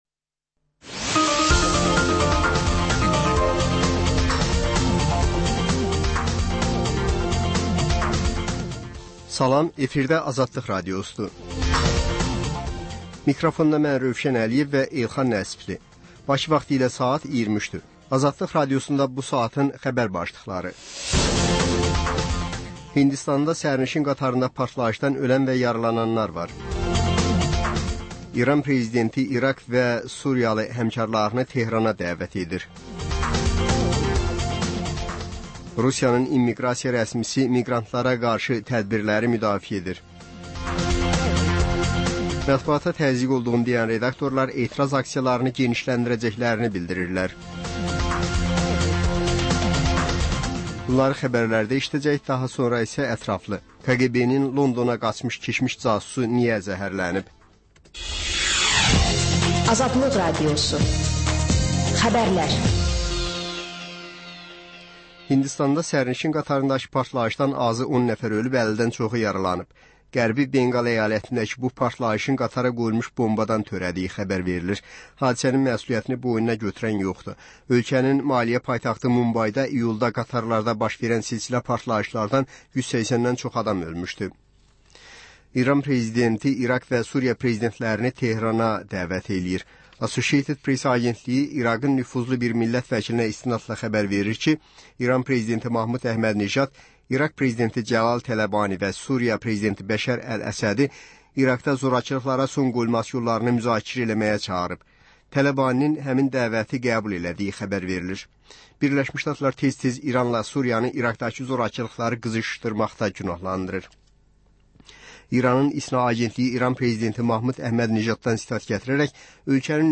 Xəbərlər, reportajlar, müsahibələr. Hadisələrin müzakirəsi, təhlillər, xüsusi reportajlar. Və sonda: Azərbaycan Şəkilləri: Rayonlardan reportajlar.